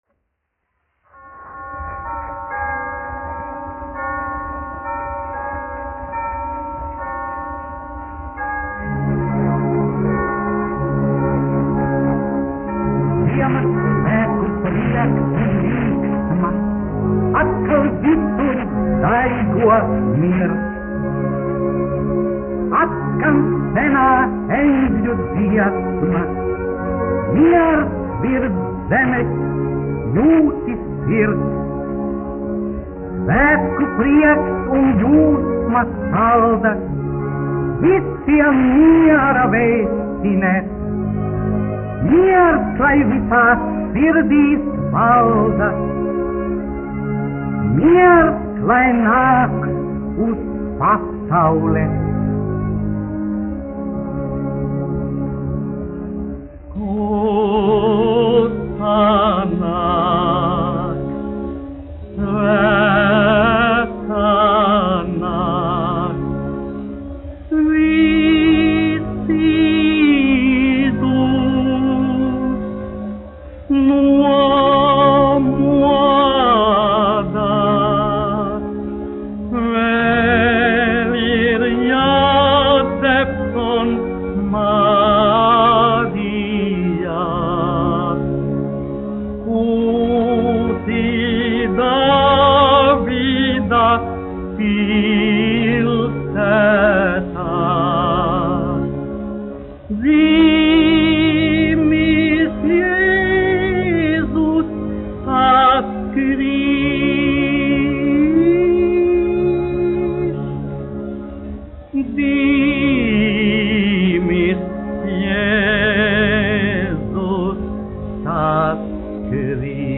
1 skpl. : analogs, 78 apgr/min, mono ; 25 cm
Ziemassvētku mūzika
Skaņuplate
Latvijas vēsturiskie šellaka skaņuplašu ieraksti (Kolekcija)